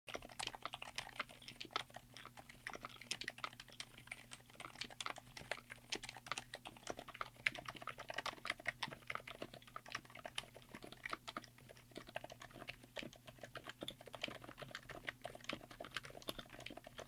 The Kailh Longhua Indigo Naturalis is the quietest switch I’ve tested so far. It features Kailh’s new Sound-Absorbing Ring design, which reduces collisions between the upper and bottom housings. These switches deliver a high-end structure at a budget-friendly price—truly impressive silent switches!